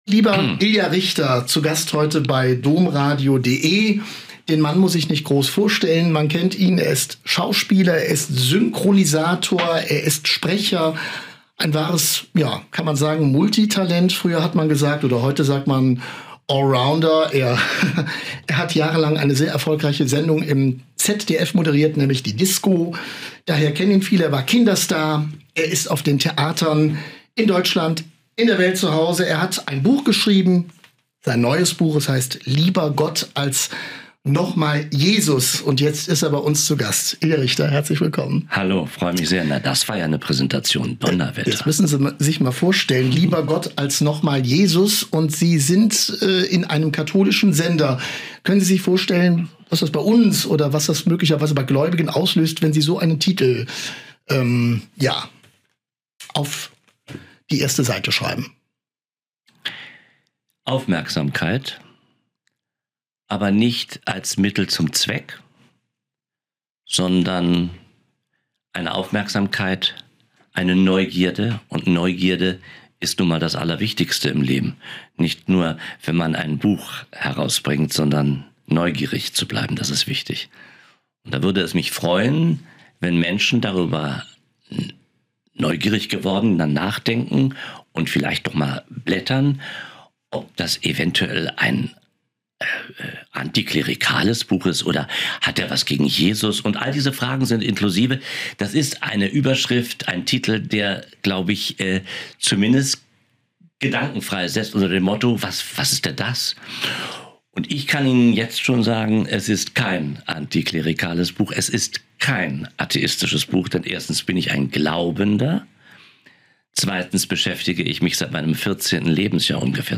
Ein Interview mit Ilja Richter (Schauspieler, Autor)